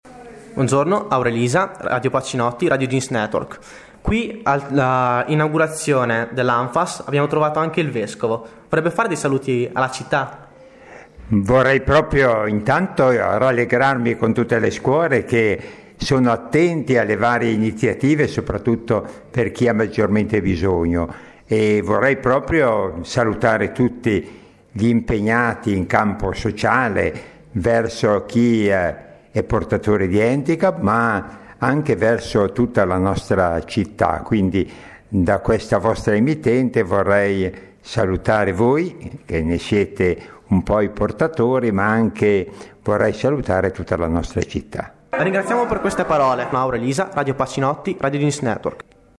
Intervista a Bassano Staffieri
play_circle_filled Intervista a Bassano Staffieri Radioweb Pacinotti Vescovo Emerito della Spezia intervista del 13/06/2012 Si è svolta nei locali dell'ANFFAS la cerimonia di riapertura dopo le traversie economiche che avevano costretto ad eliminare il supporto ai disabili, qui abbiamo incontrato dirigenti e personalità politiche e religiose.